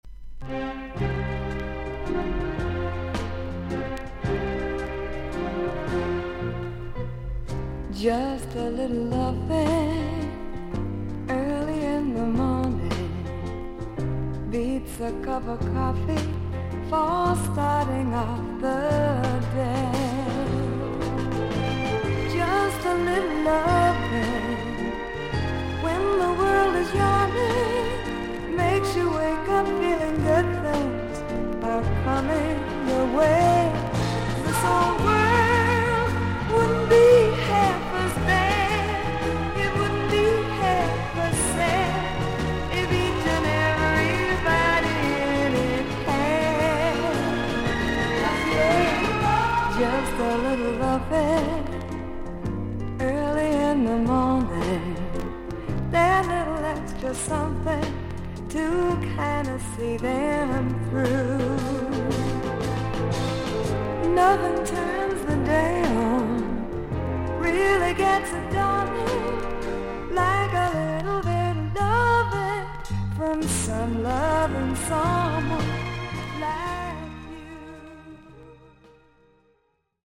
女性シンガー。ソフィスティケイトされたR&B/ソフト・ロック・テイストのサウンドが心地よい名アルバム。
クリアな音です。